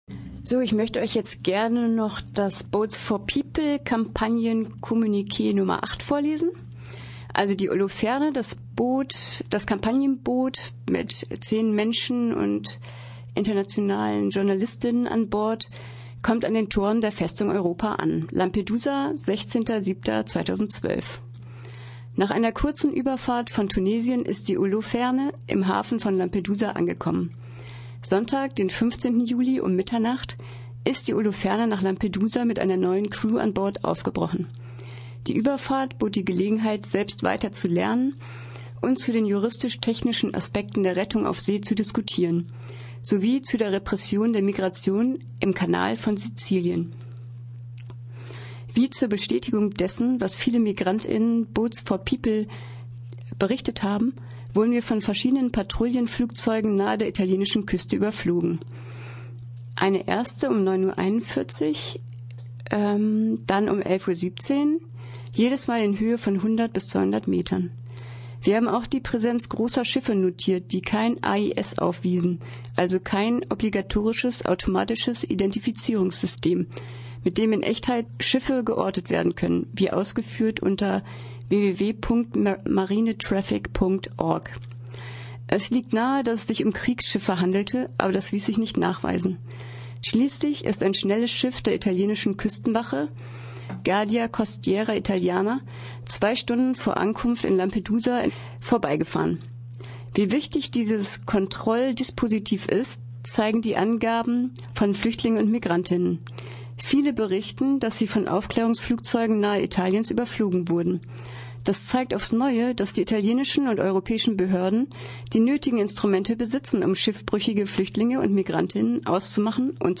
Aus dem Kommunique Nr.8 der boats4people Kampagne wird ein Auszug vorgelesen .Das Kampagnenboot hat viele Kontrollflugzeuge und Militärschiffe im Mittelmeer gesehen, das bestätigt die Berichte von Migrant_innen und stellt die Frage ,waru